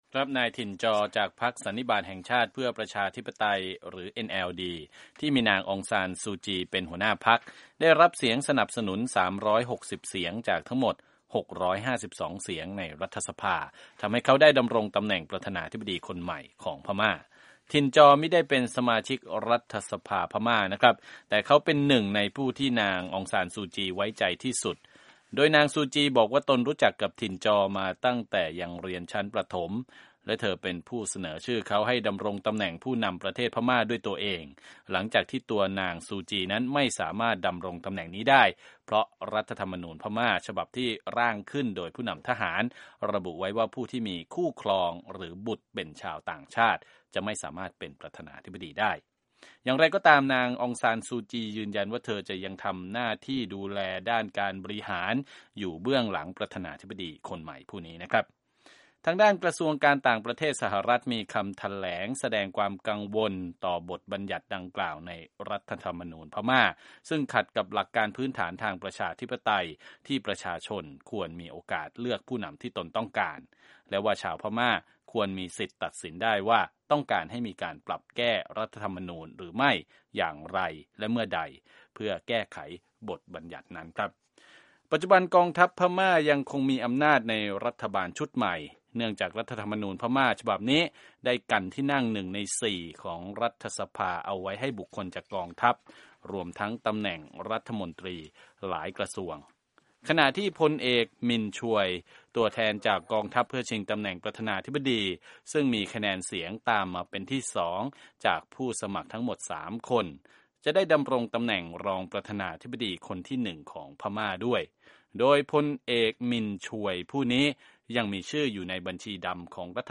รายงานจากกรุงเนปิดอว์ของพม่า